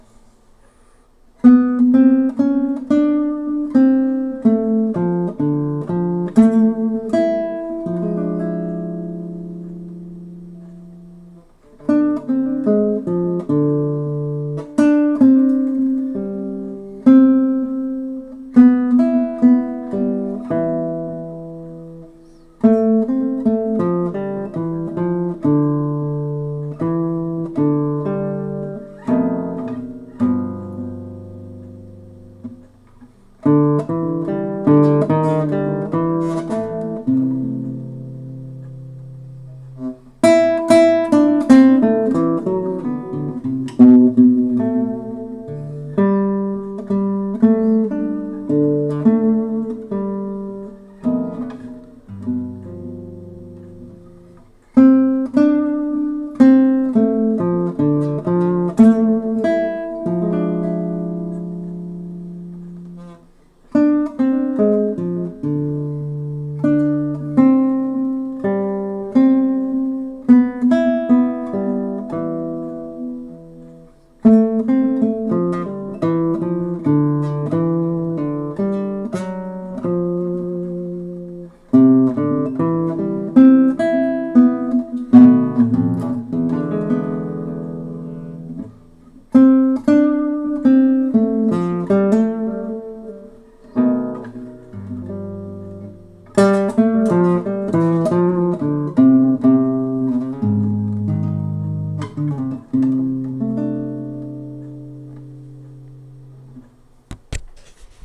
Live Classical Guitar performances